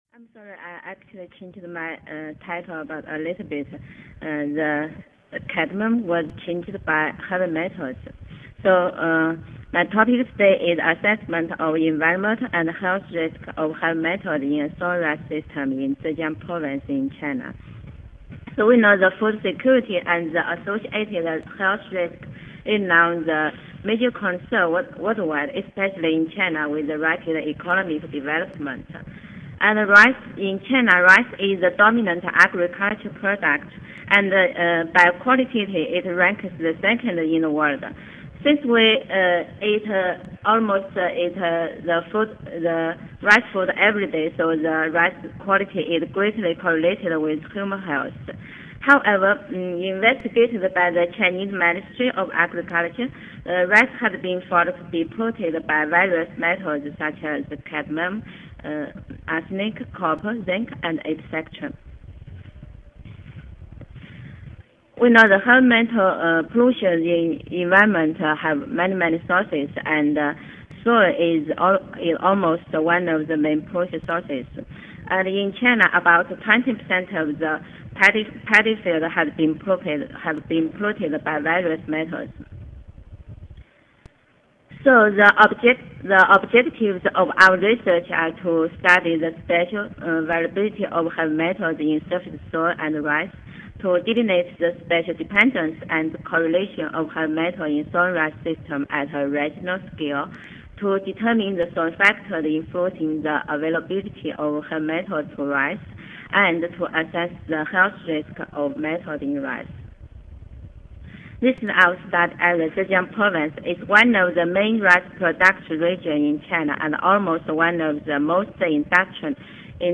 Zhejiang University Audio File Recorded presentation